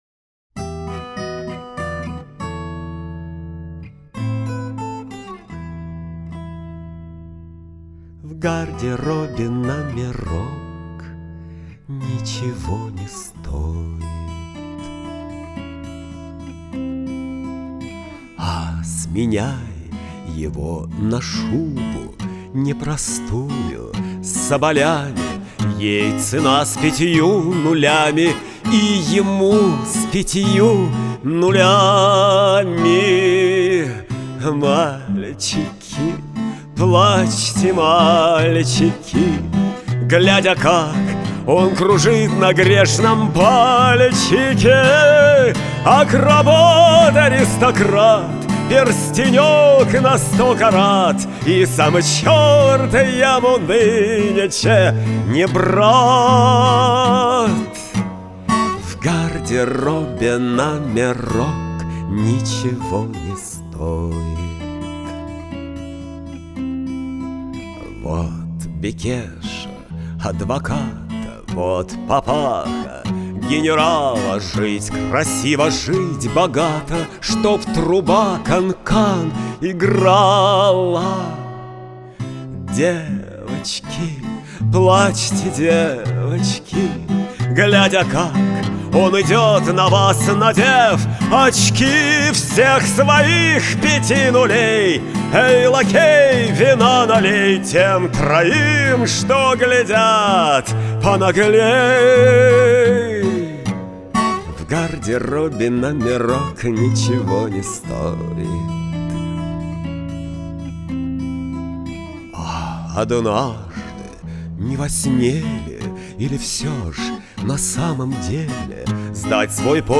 Примеры песен под гитару
Примечание: Некоторые записи из перечисленных ниже делались на природе: в горах, в лесу, с помощью мобильного модуля студии. Поэтому пусть вас не удивляет аккомпанемент ветра, цикад (днем) или сверчков (ночью).
исполняет автор